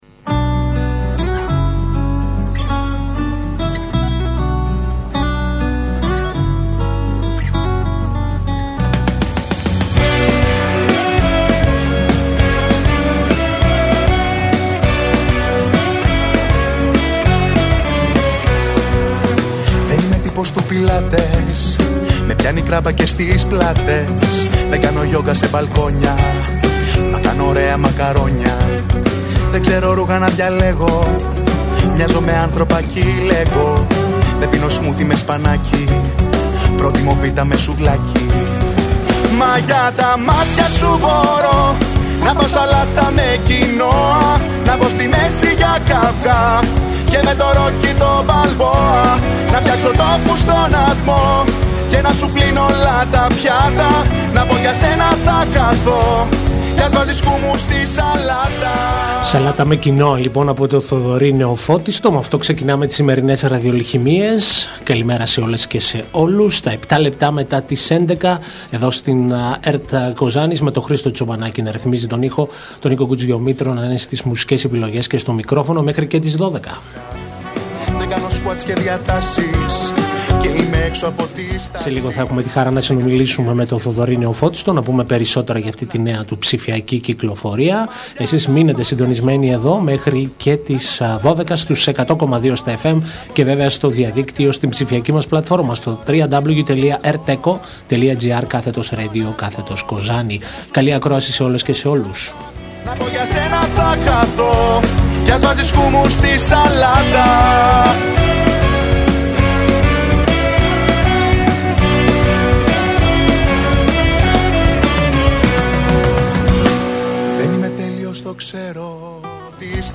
Μια εκπομπή μουσικής και λόγου διανθισμένη με επιλογές από την ελληνική δισκογραφία.